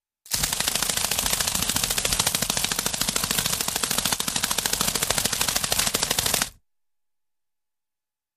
RapidInsectWing CRT042201
Steady, Rapid, Insect-like Wings Flap ( I.e. Fairy Wings Or Insect Wings ).